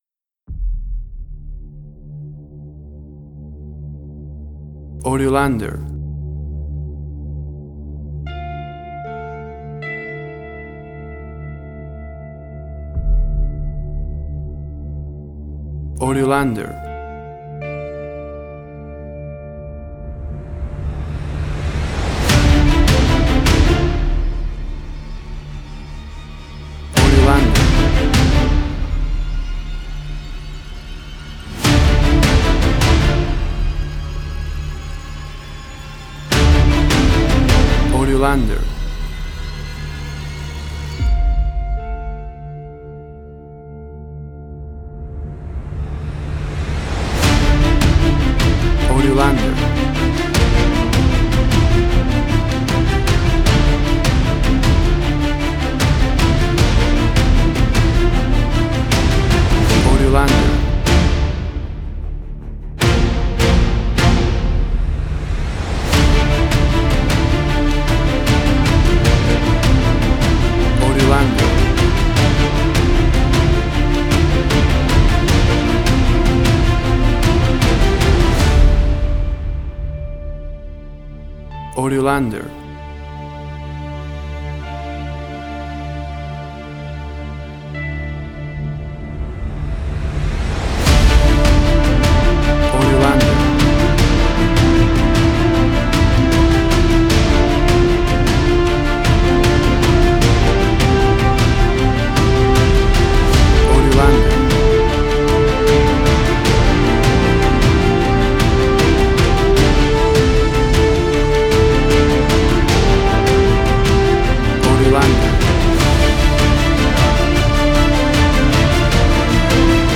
WAV Sample Rate: 16-Bit stereo, 44.1 kHz
Tempo (BPM): 153